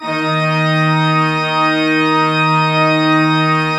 Index of /90_sSampleCDs/Propeller Island - Cathedral Organ/Partition I/PED.V.WERK R